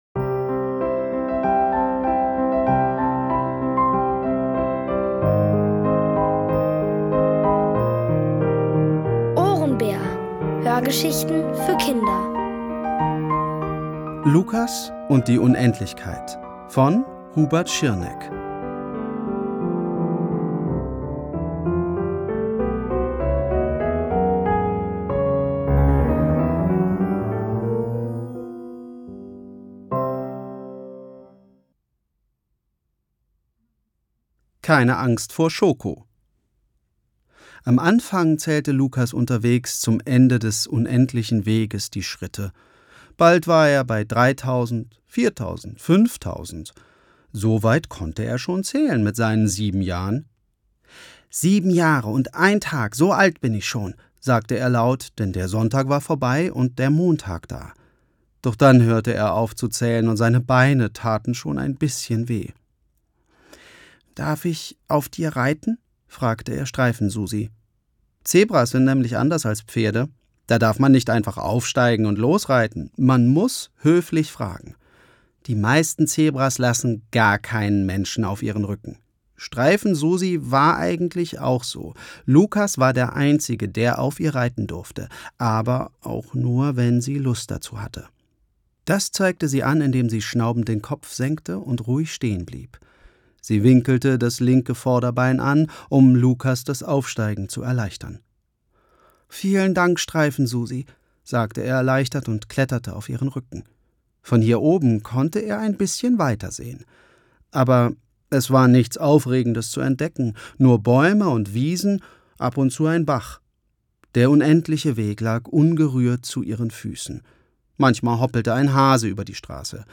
Von Autoren extra für die Reihe geschrieben und von bekannten Schauspielern gelesen.
OHRENBÄR-Hörgeschichte: Lukas und die Unendlichkeit (Folge 3 von 7)